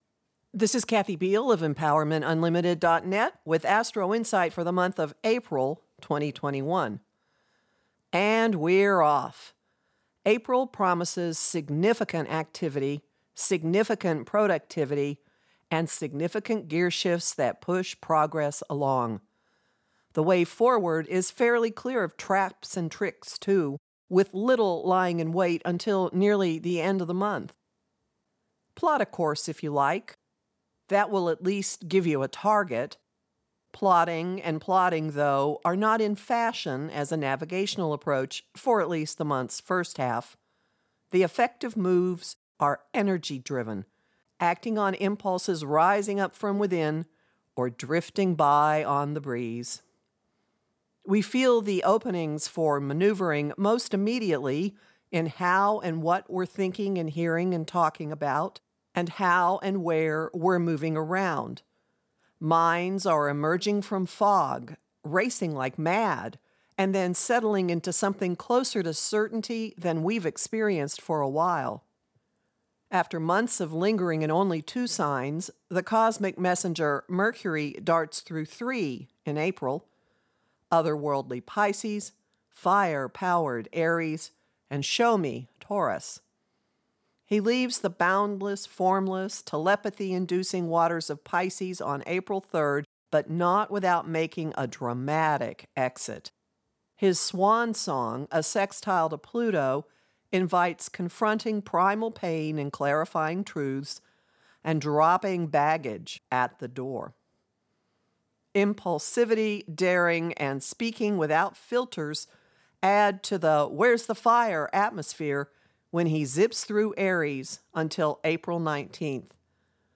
To listen to Astrologer